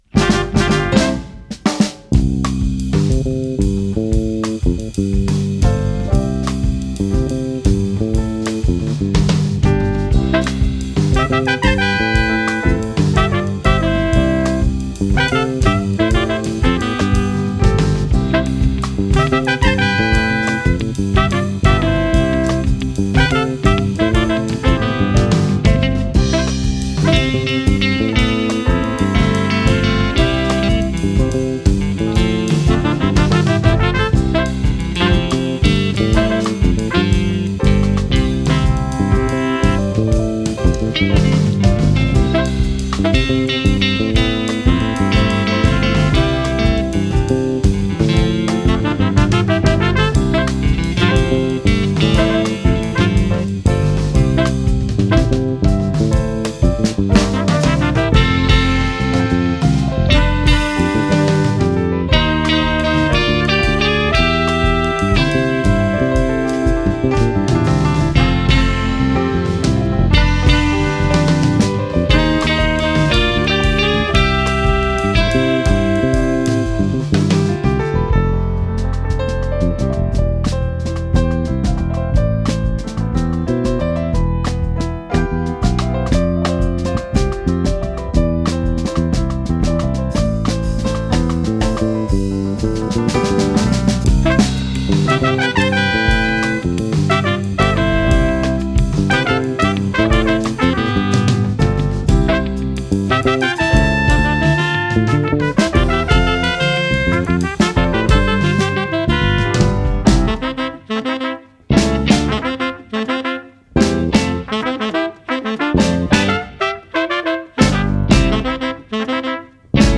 Versió cantada: